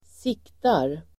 Uttal: [²s'ik:tar]